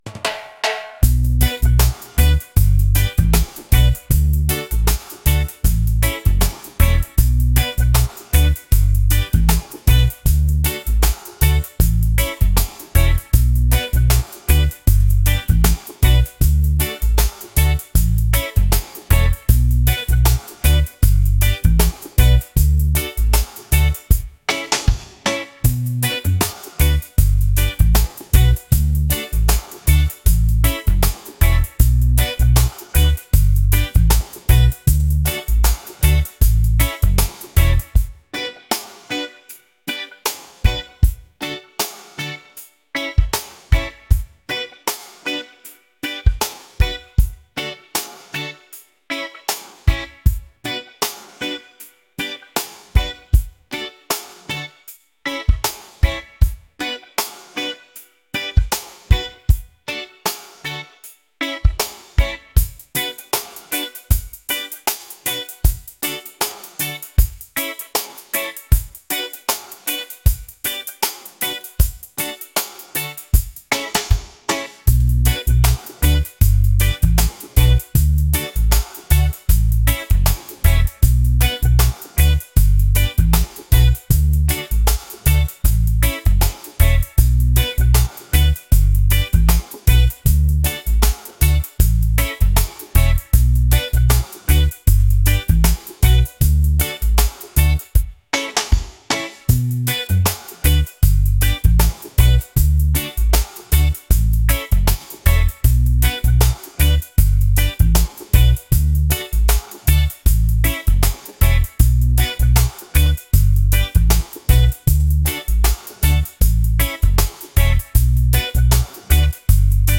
upbeat | laid-back | reggae